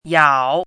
chinese-voice - 汉字语音库
yao3.mp3